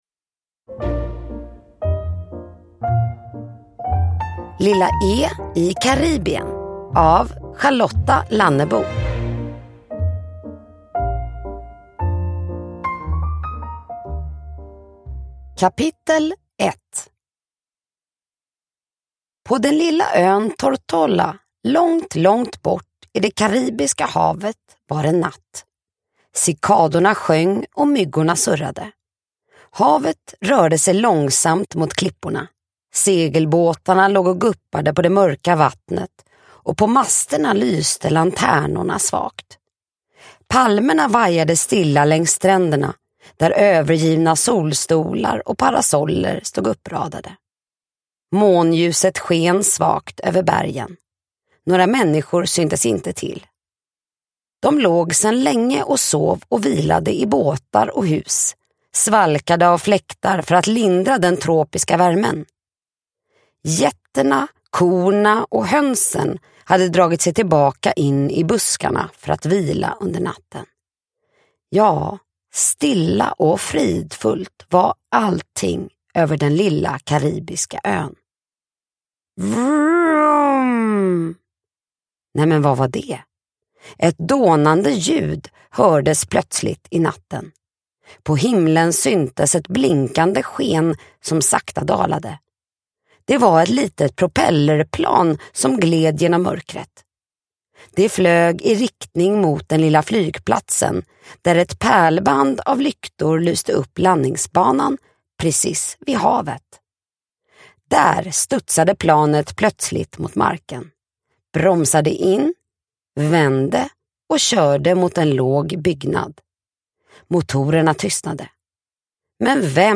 Lilla E i Karibien – Ljudbok – Laddas ner